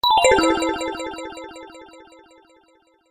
鉄琴の一種のヴィブラフォン音色。